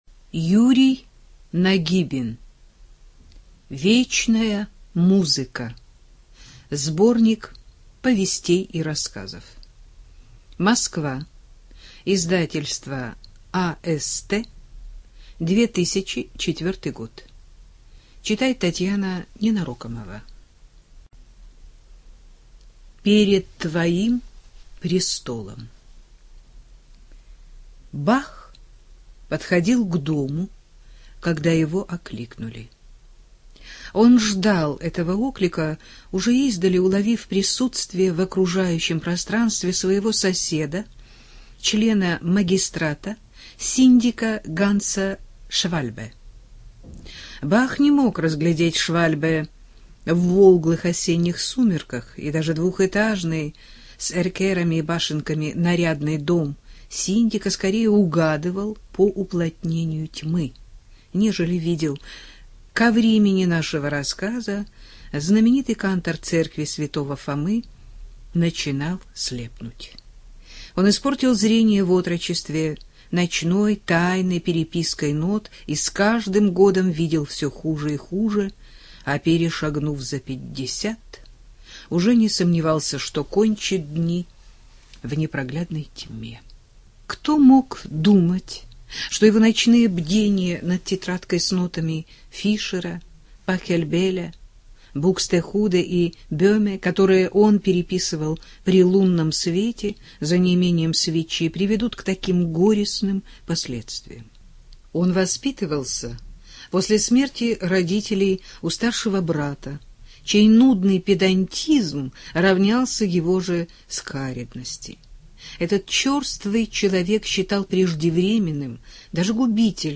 Студия звукозаписизвукотэкс